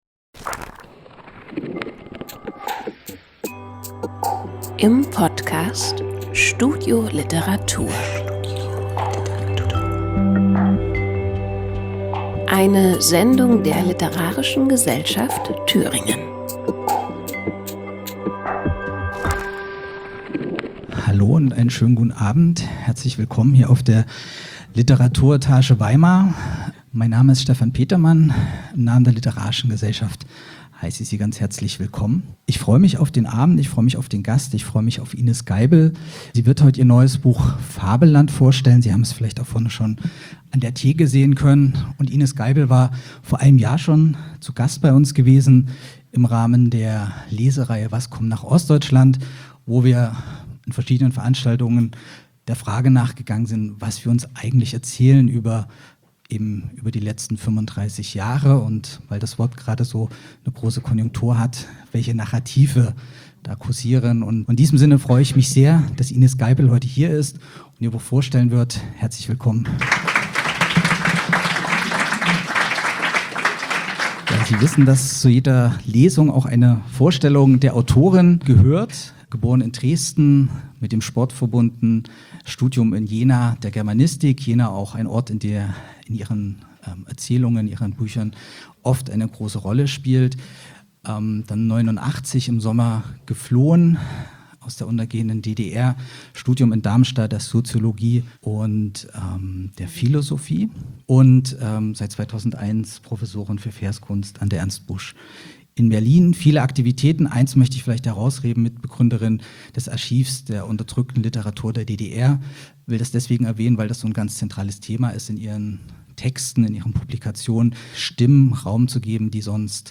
Aufzeichnung einer Veranstaltung in der Weimarer LiteraturEtage vom 4. Dezember 2024